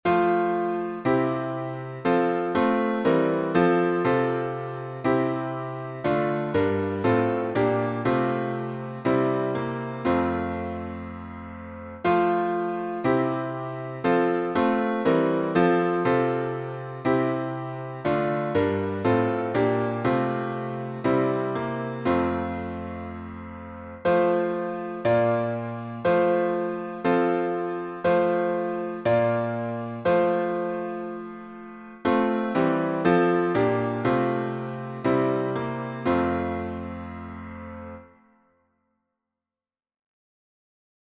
Words by Frances R. Havergal Tune: R. H by Lowell Mason Key signature: F major (1 flat) T